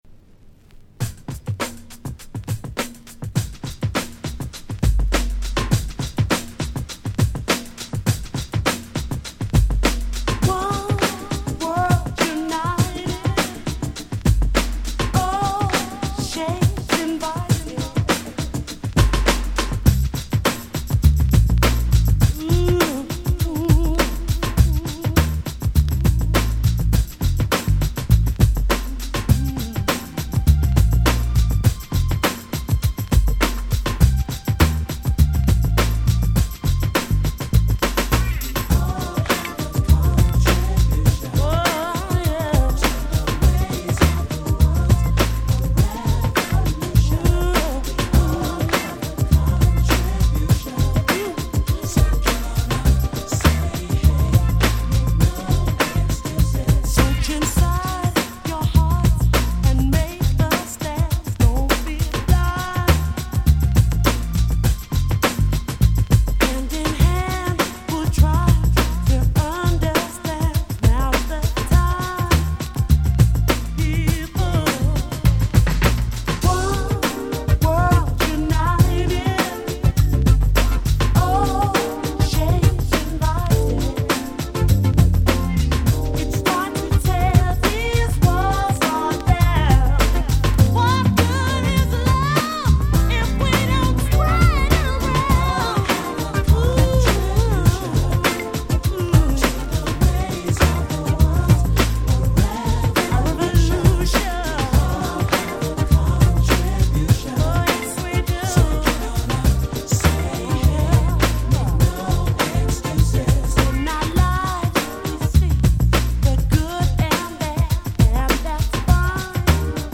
90' Smash Hit UK R&B !!
彼女の涼しげなVocalとGround Beat調のトラックが大変心地の良いUK R&B Classicsです！！